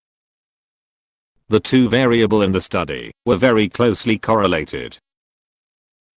Q110. Write from dictation - PTE
You will hear a sentence. Type the sentence or you can write on paper or notepad and check the answer by clicking on show answer after every dictation.